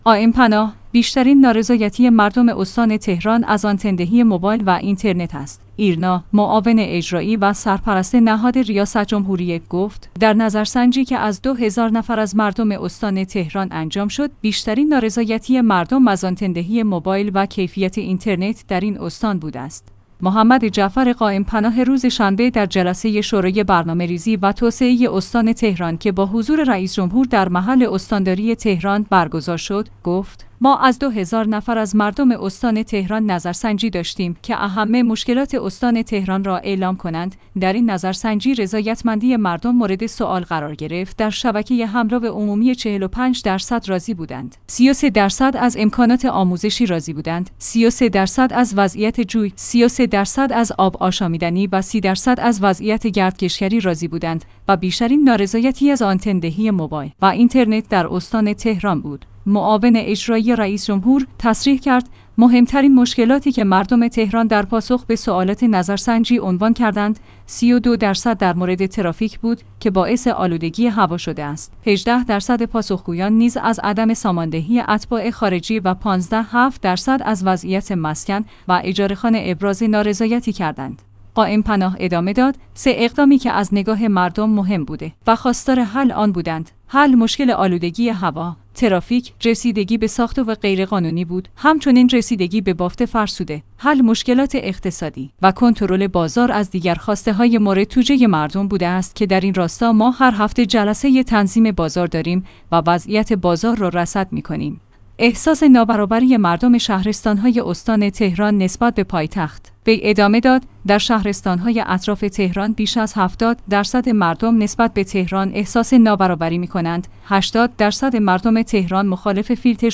ایرنا/معاون اجرائی و سرپرست نهاد ریاست جمهوری،گفت: در نظر سنجی که از دو هزار نفر از مردم استان تهران انجام شد بیشترین نارضایتی مردم از آنتن دهی موبایل و کیفیت اینترنت در این استان بوده است. محمد جعفر قائم پناه روز شنبه در جلسه شورای برنامه ریزی و توسعه استان تهران که با حضور رئیس جمهور در محل استاند